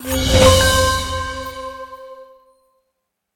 getpoints.ogg